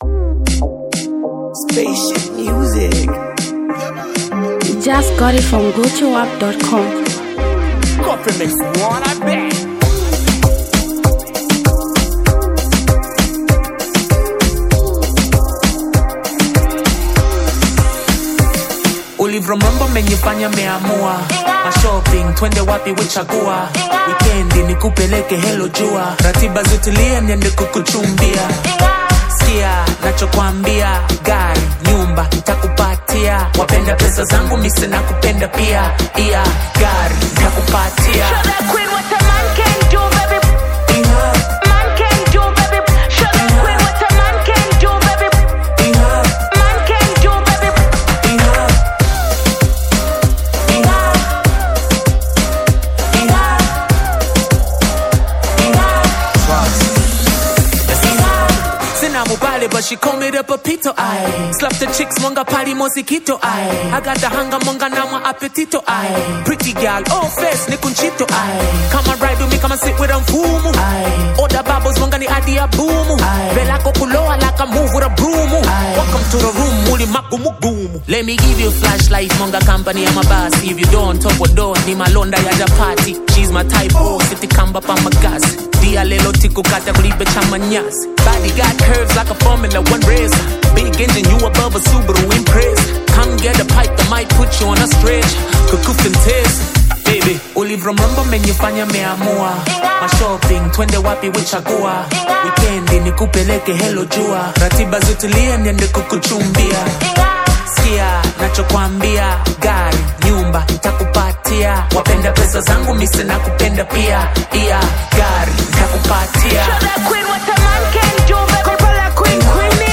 Zambian Mp3 Music
buzzing lovable rhyme sound